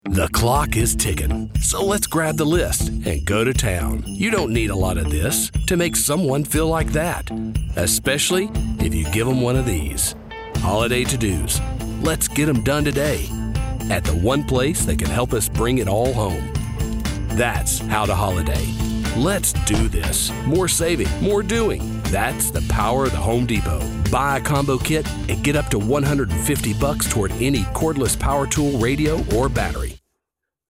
North American General, North American Southern
Commercial
He operates from a professional home studio equipped with an MKH416 microphone and Logic Pro, ensuring broadcast-quality audio with fast turnaround times.